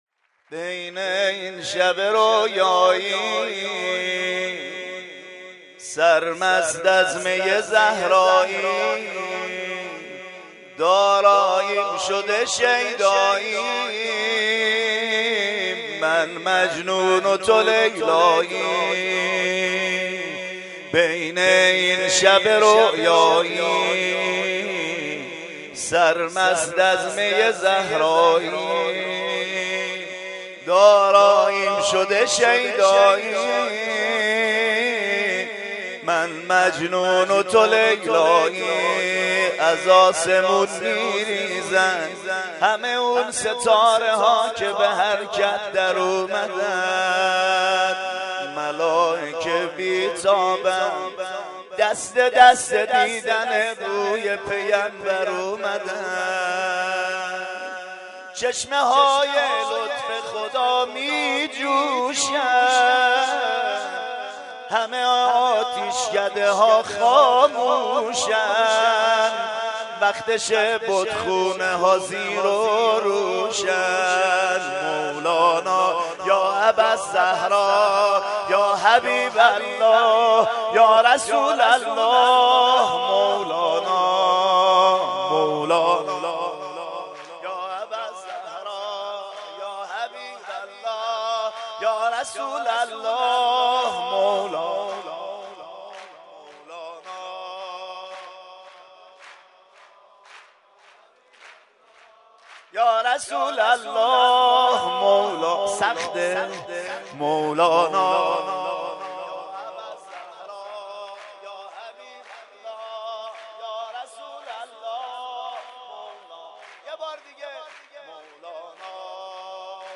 جشن میلاد حضرت رسول و امام صادق(ع)مهدیه امام حسن مجتبی
مدح